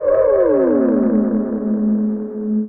Theremin_FX_09.wav